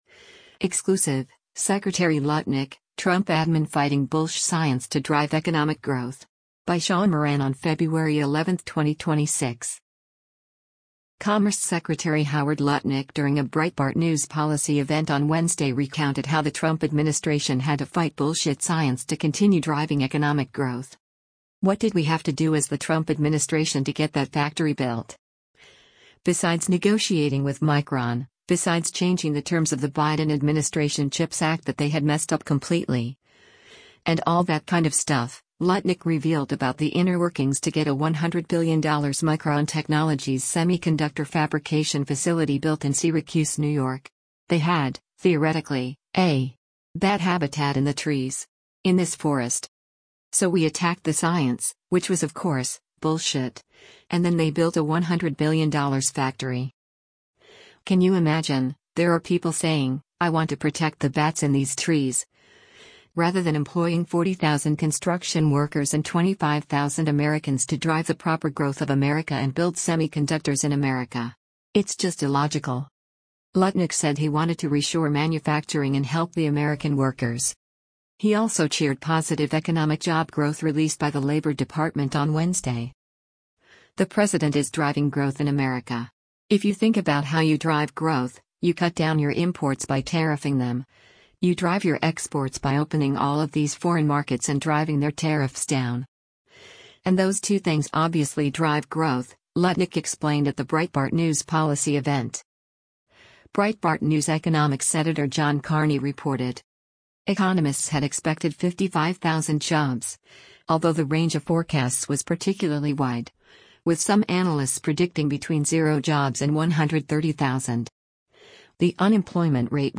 Commerce Secretary Howard Lutnick during a Breitbart News policy event on Wednesday recounted how the Trump administration had to fight “bullshit” science to continue driving economic growth.